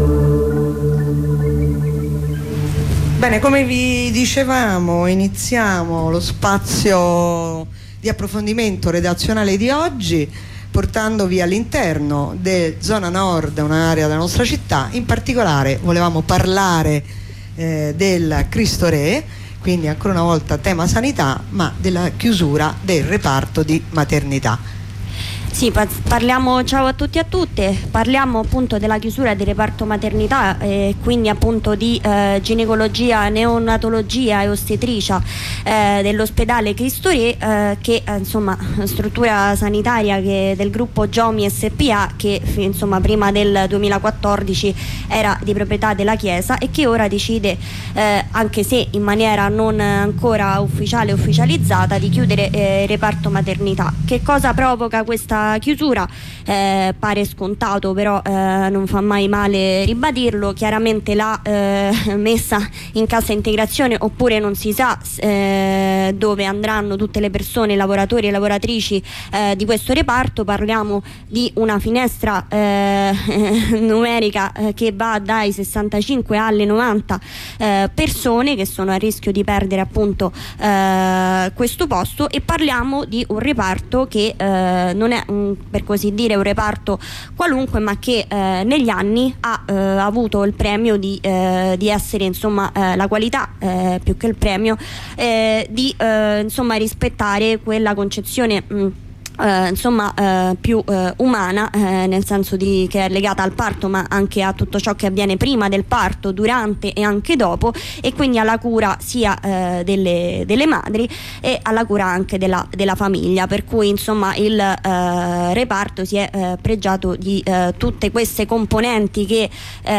Corrispondenza con un'avvocata del Legal Team, dopo la mattinata di identificazioni